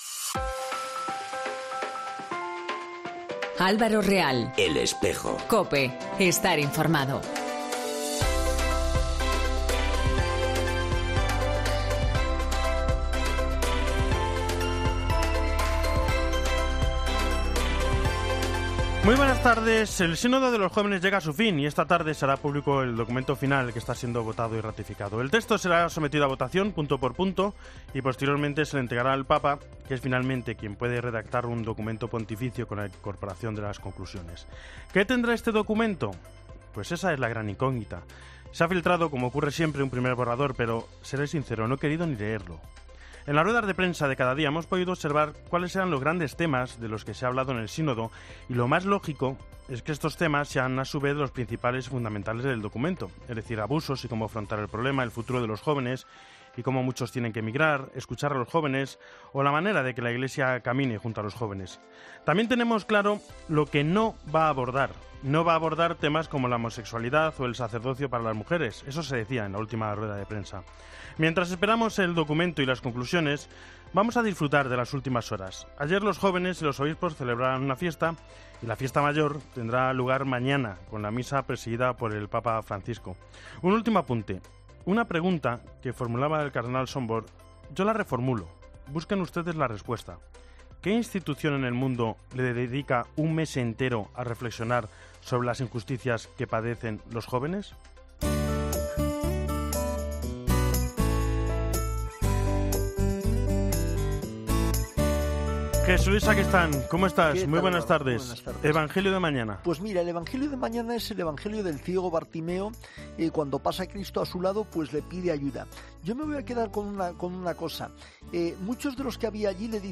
La entrevista sobre pastoral...